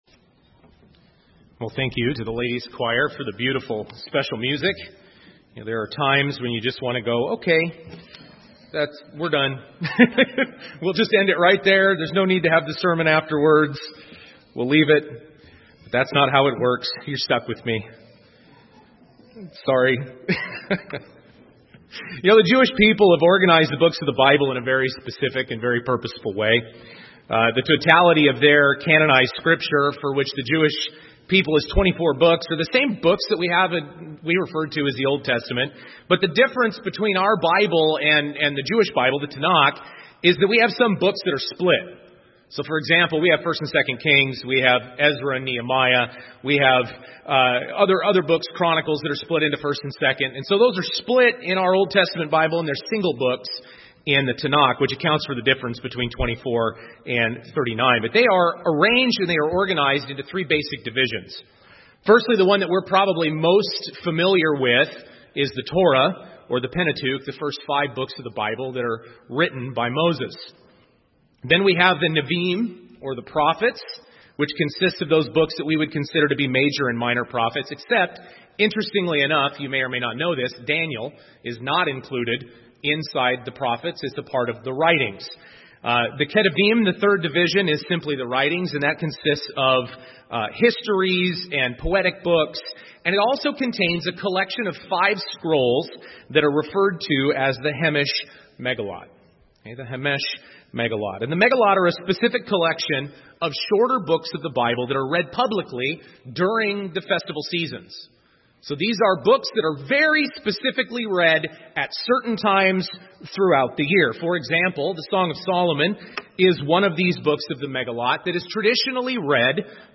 There's no need to have the sermon afterwards.